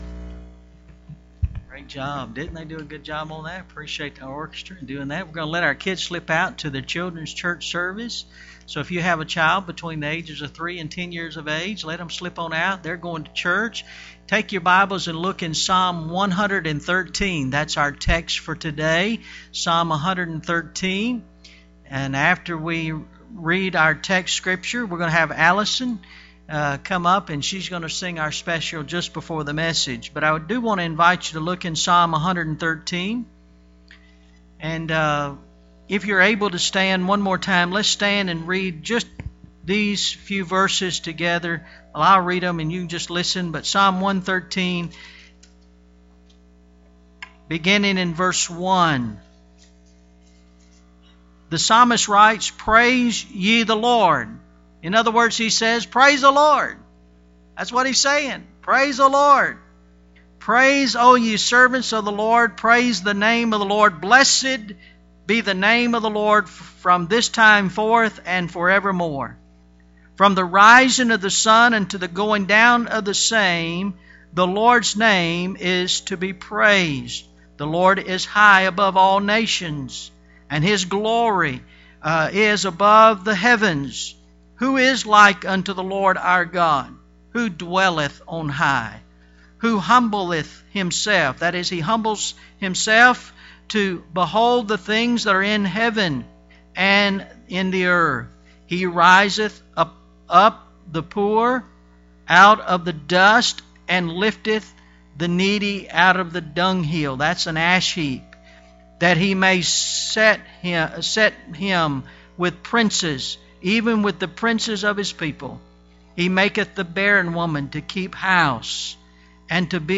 Praise The Lord – November 9th, 2014 – AM Service